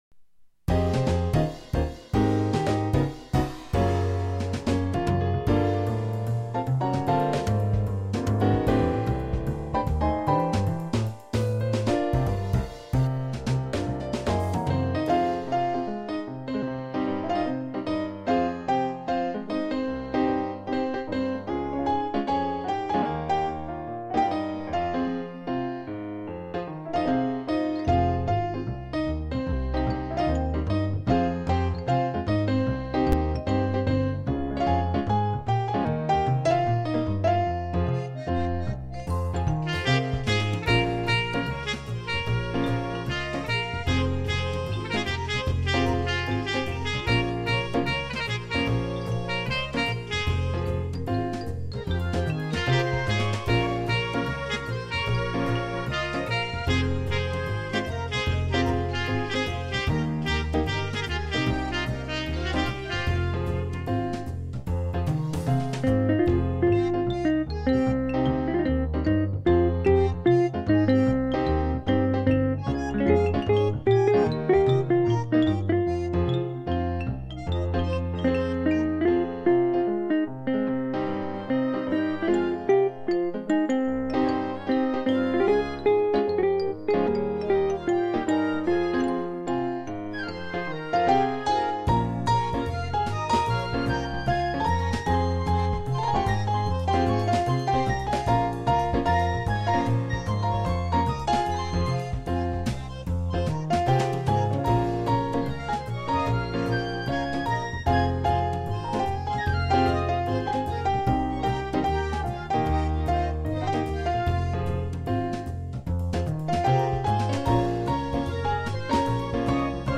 באזור של 1:15 יש קצת הרגשה של בלגן- אבל סה"כ זה ממש טוב!
מנגינה קלילה כזאת וזורמת..